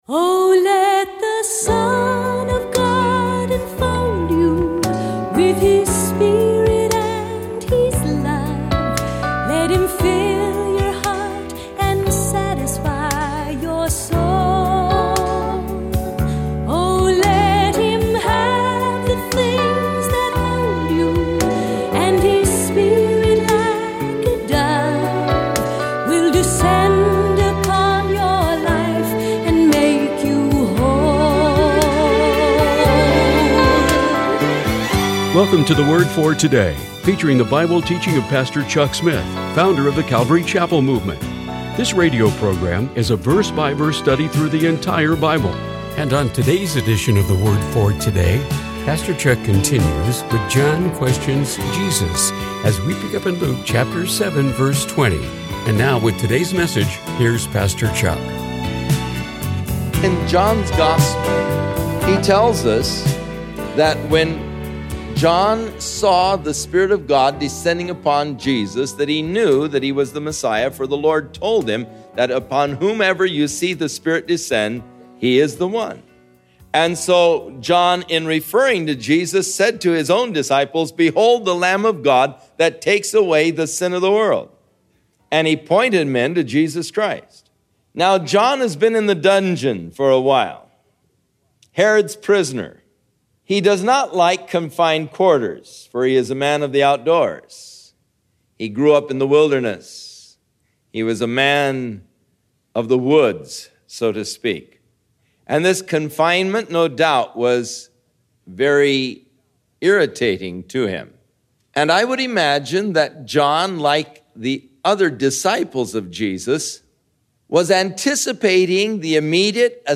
This radio program is a verse by verse study through the entire Bible.